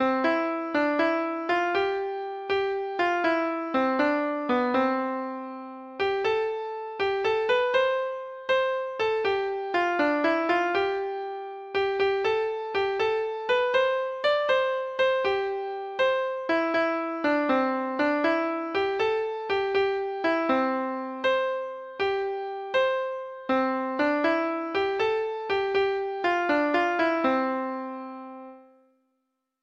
Folk Songs from 'Digital Tradition' Letter T The Golden Vanity
Free Sheet music for Treble Clef Instrument